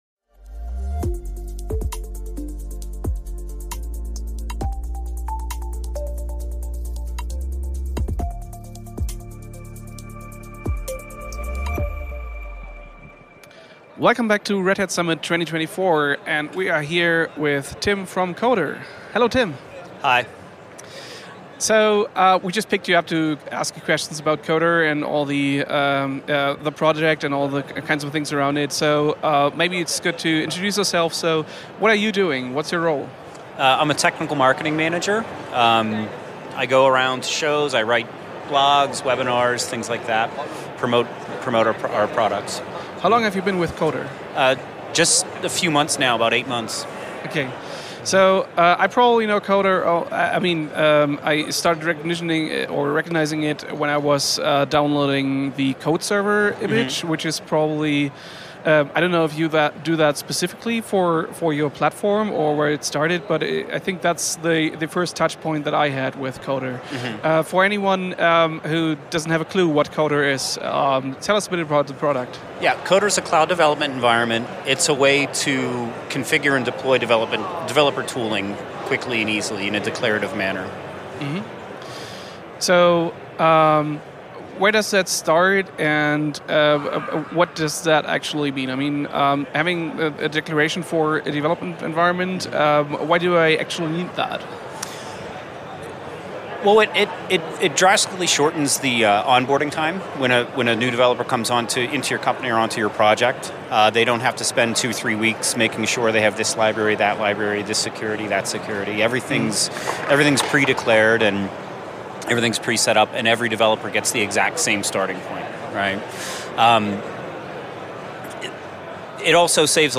vom Red Hat Summit in Denver.